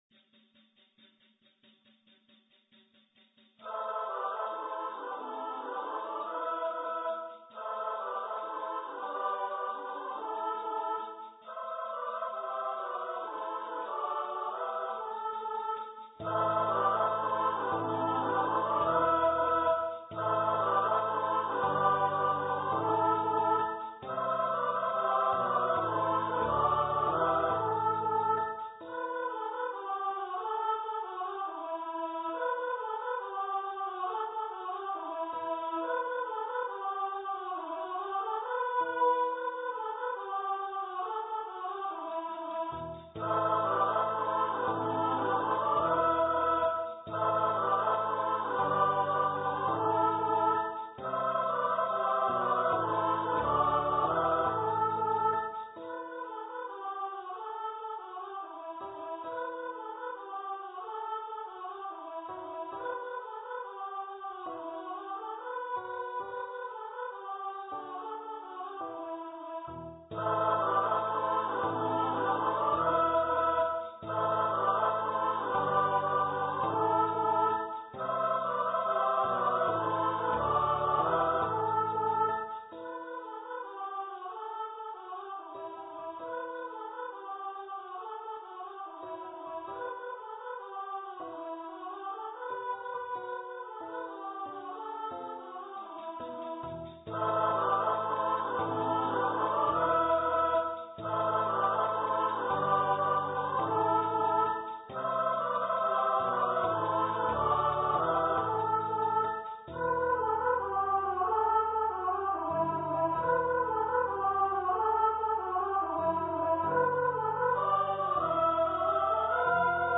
for female voice choir, piano and percussion
Choir - 3 part upper voices